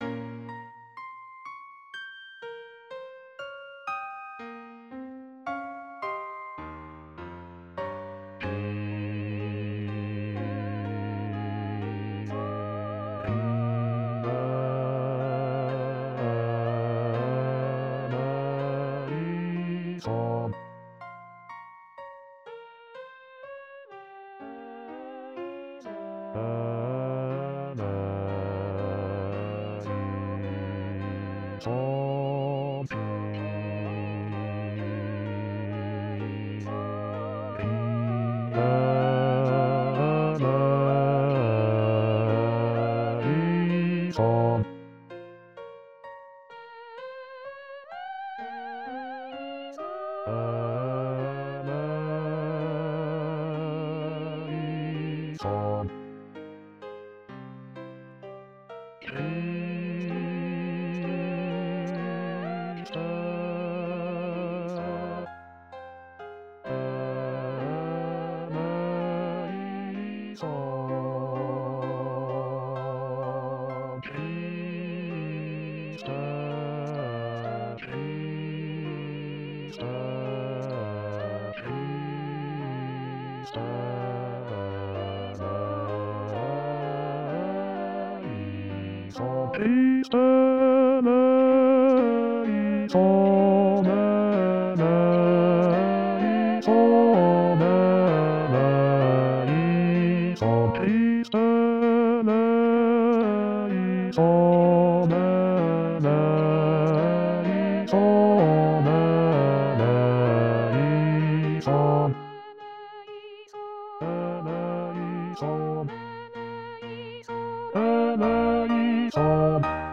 Messa-du-Gloria-Kyrie-Basse.mp3